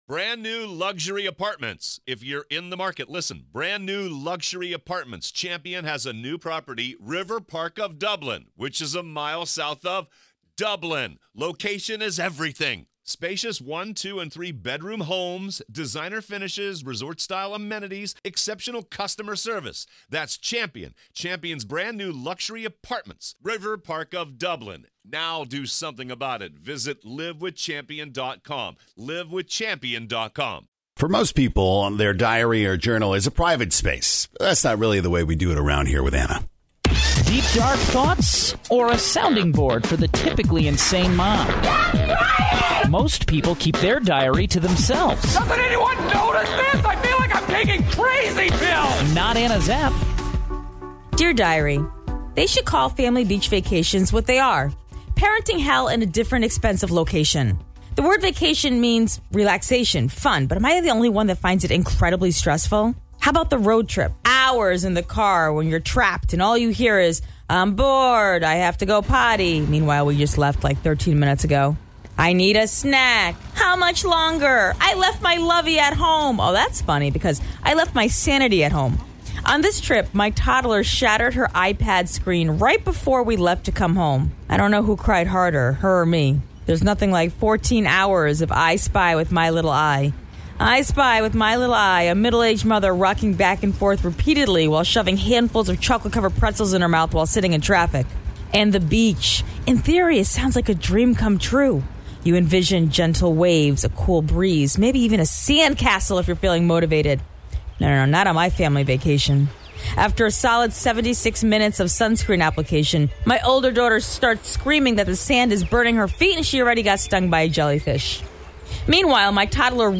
A Reading